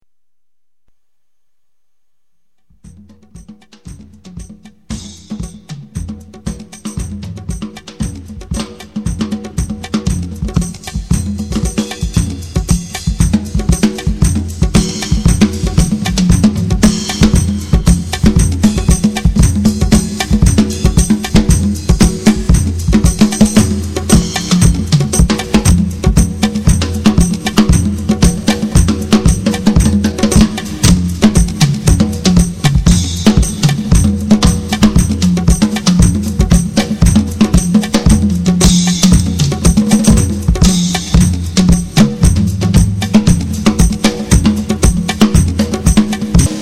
Drum Percussion
[Audio MP3 autoprodotti]